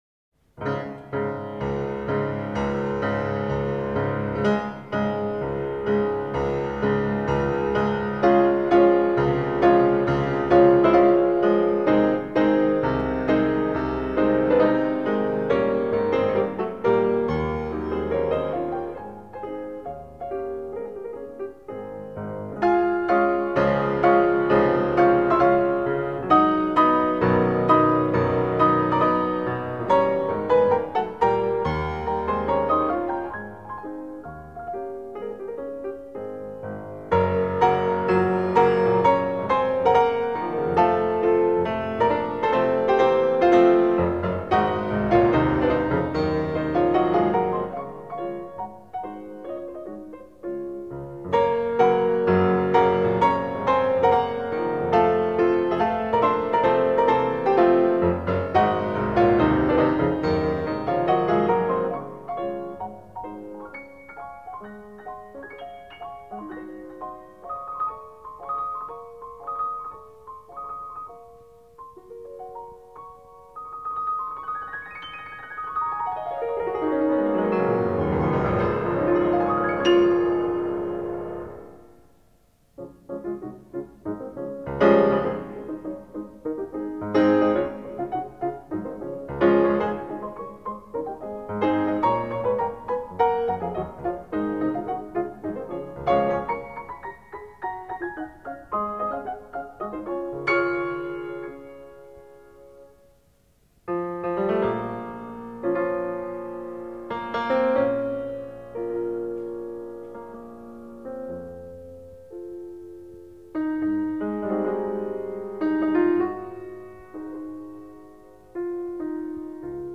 Martha Argerich, piano. Franz Liszt: Hungarian Rhapsody no. 6.